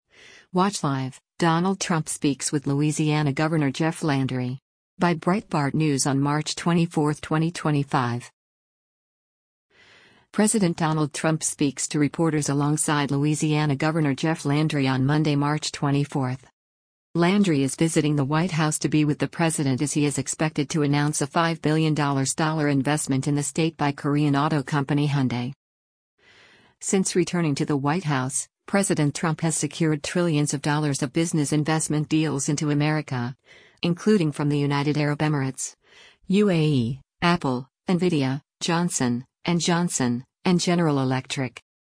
President Donald Trump speaks to reporters alongside Louisiana Governor Jeff Landry on Monday, March 24.